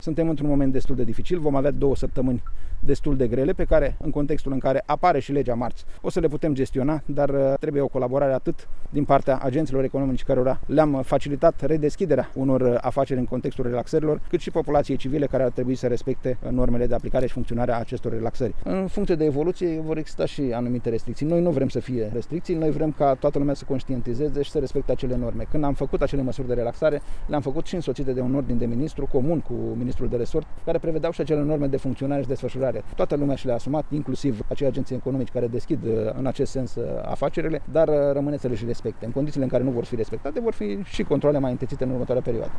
Nelu Tătaru a tras şi un semnal de alarmă – pe litoral şi în staţiunile de pe Valea Prahovei se constată numeroase încălcări ale regulilor de prevenire a răspândirii coronaviruslui, astfel că NU exclude posibilitatea introducerii unor noi restricţii: